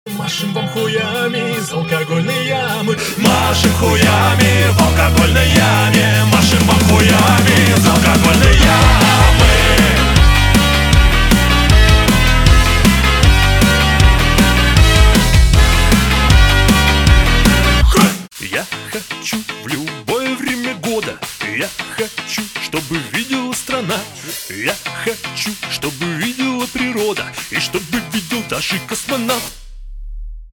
• Качество: 320, Stereo
веселые
ска-панк